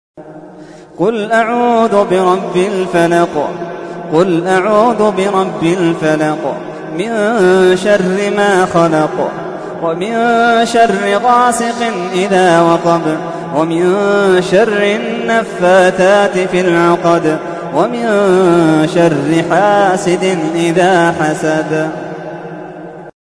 تحميل : 113. سورة الفلق / القارئ محمد اللحيدان / القرآن الكريم / موقع يا حسين